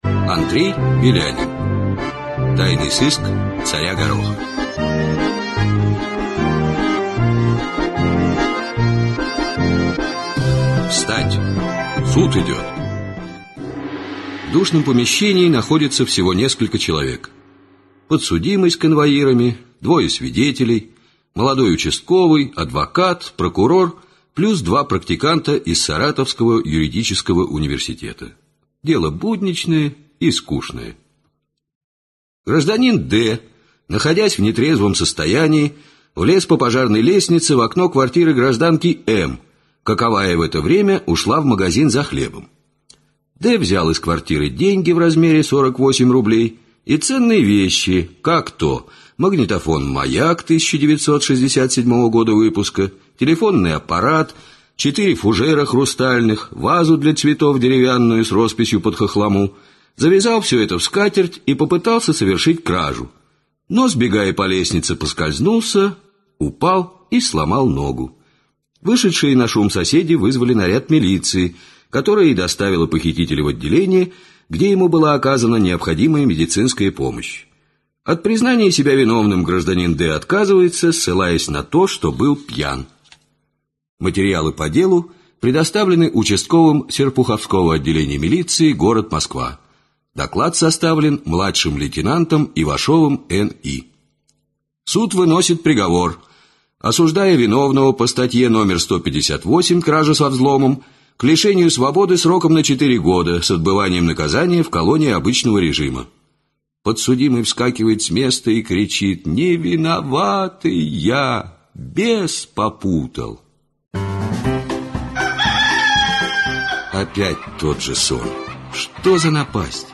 Аудиокнига Тайный сыск царя Гороха | Библиотека аудиокниг